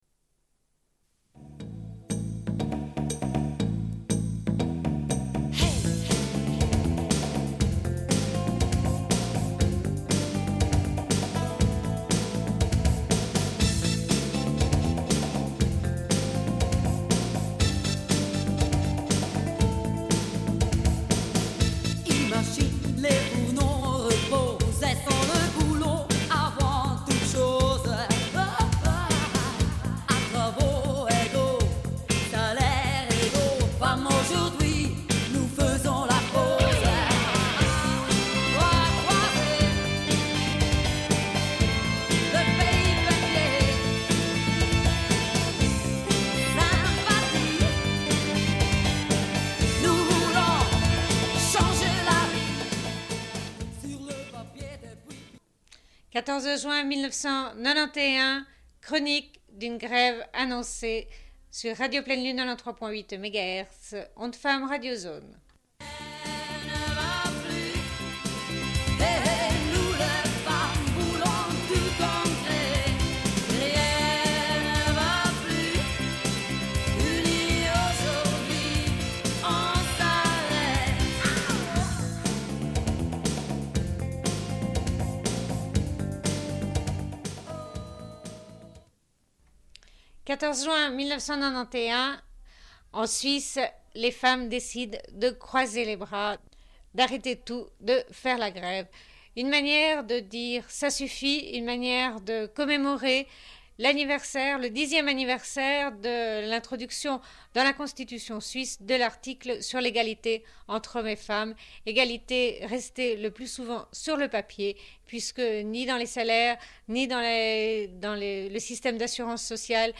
Une cassette audio, face A31:31
Interview avec Christiane Brunner, secrétaire syndicale à la Fédération suisse des travailleurs de la métallurgie et de l'horlogerie (FTMH), syndicat à l'origine de cette grève.
Rencontre avec des travailleuses de l’hôpital.
Entretien avec des travailleuses de la Maternité, notamment des sages-femmes.